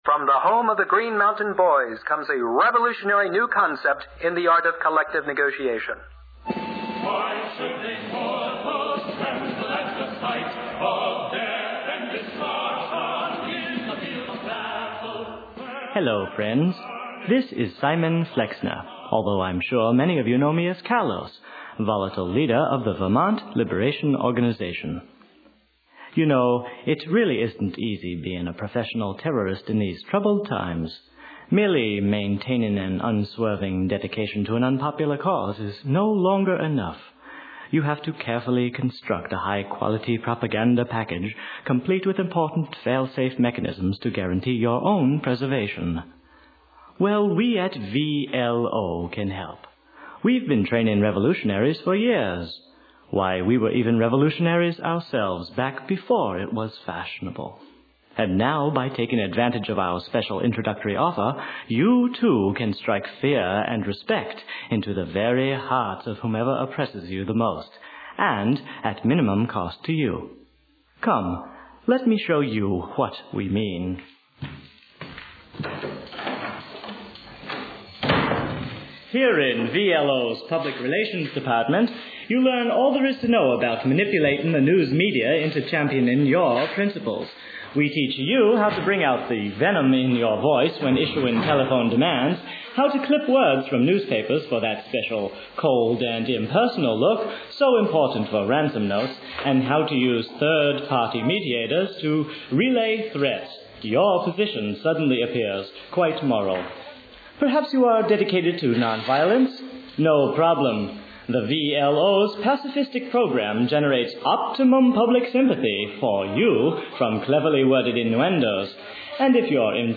In the meantime, here are some lo-fi versions of the radio programs... these were done in 1978.